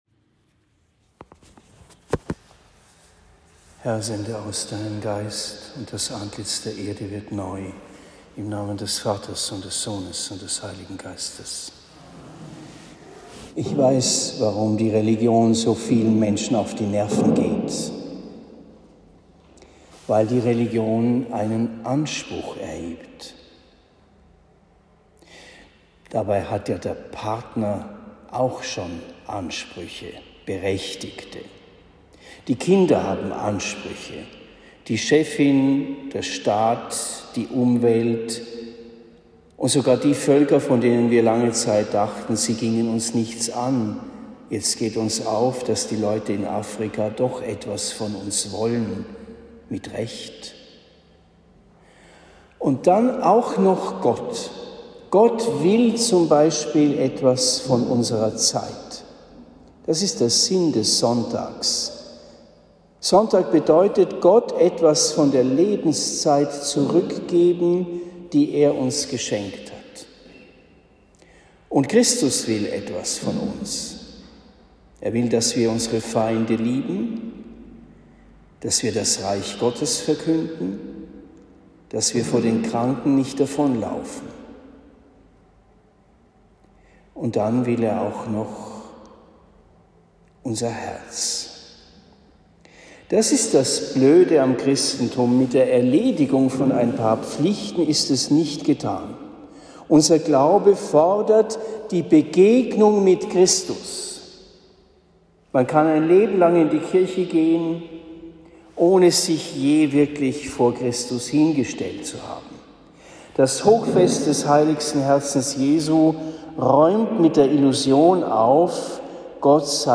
Predigt in Esselbach am 15. Juni 2023